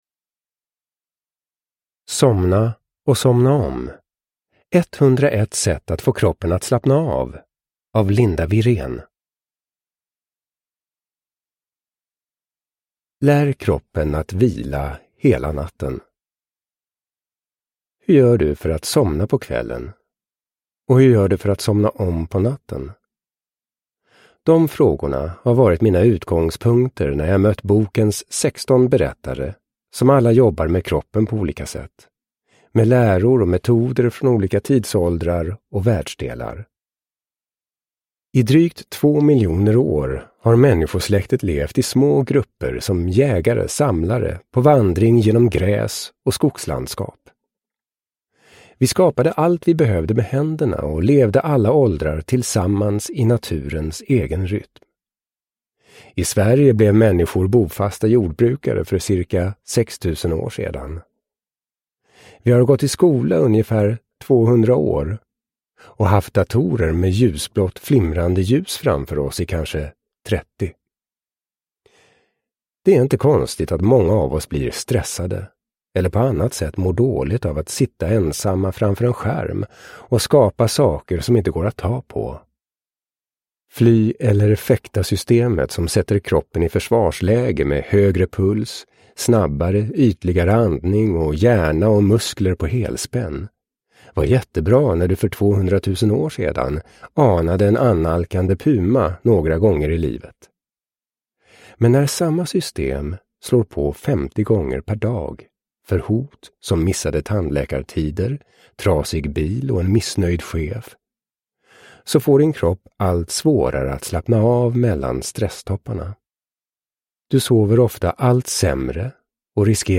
Somna & somna om : 101 sätt att få kroppen att slappna av – Ljudbok – Laddas ner